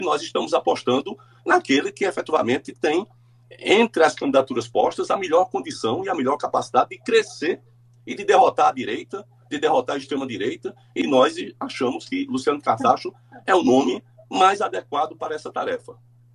O comentário de Coutinho foi registrado pelo programa Correio Debate, da 98 FM, de João Pessoa, nesta segunda-feira (08/04).